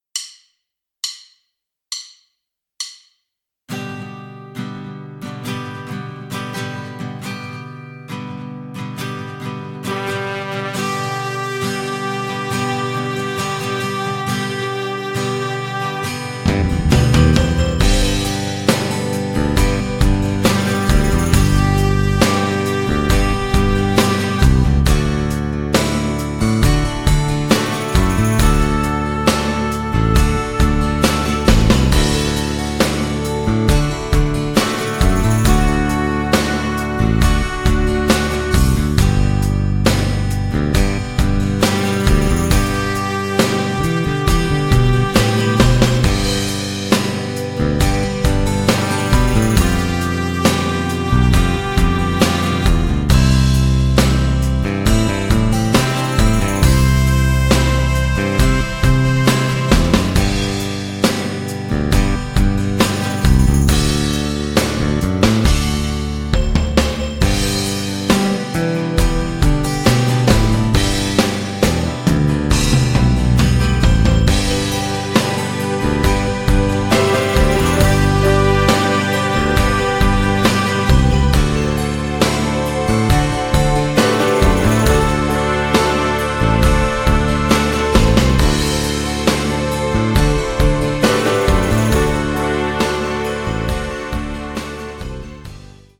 Instrumental, Karaoke